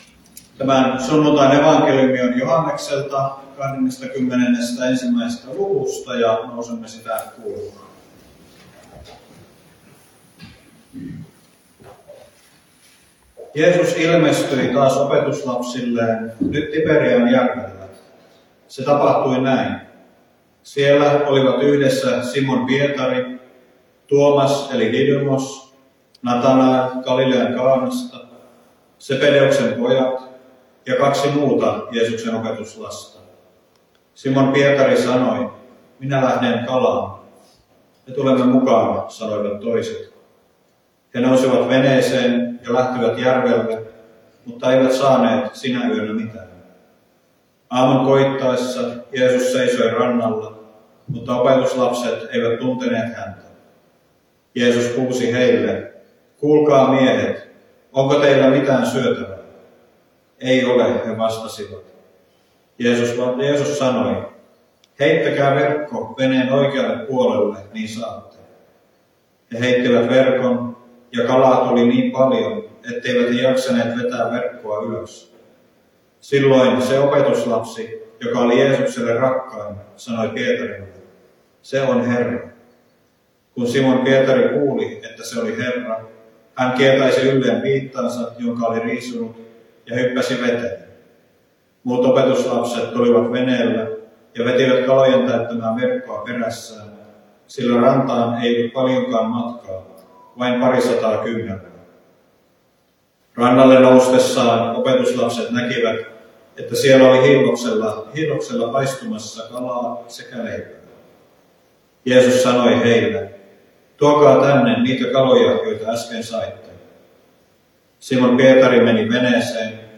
Soini